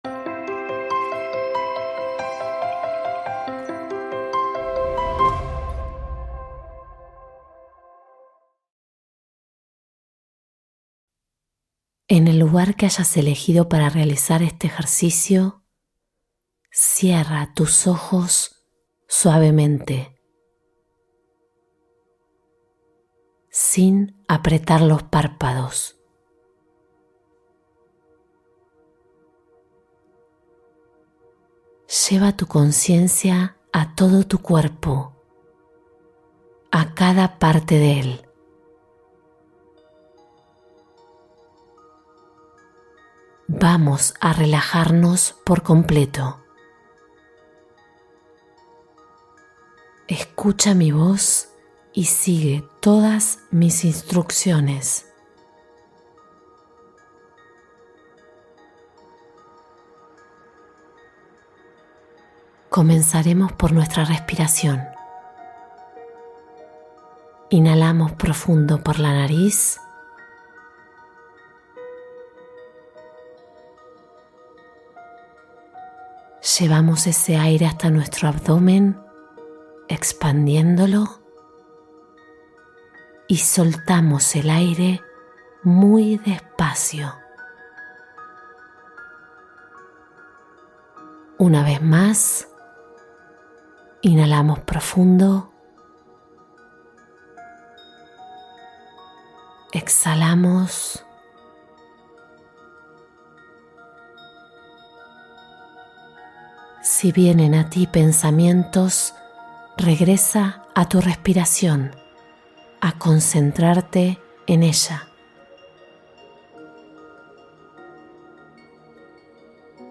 Calma para la Ansiedad: Meditación de Regulación del Sistema Nervioso